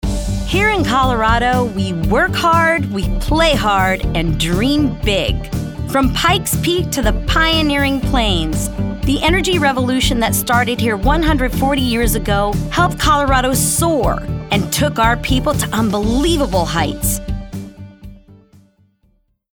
announcer, Booming, cool, promo, upbeat